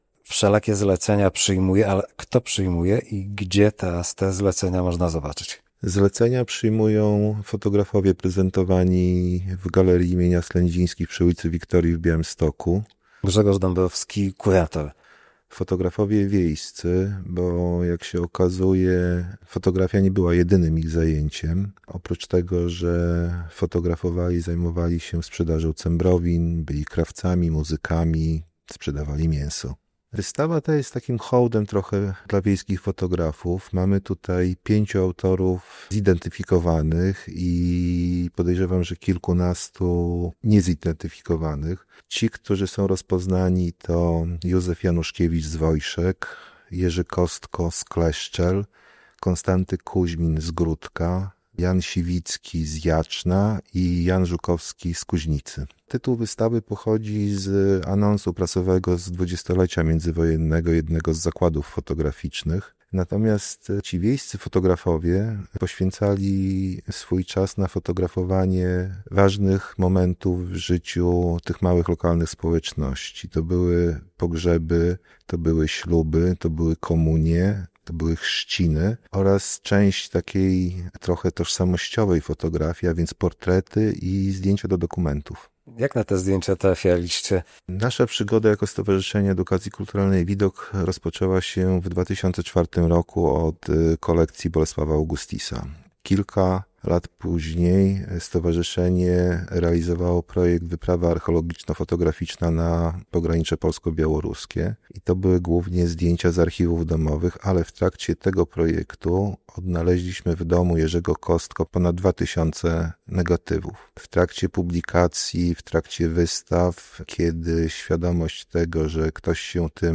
Z kuratorem rozmawiał